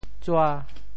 潮语发音
tsua5.mp3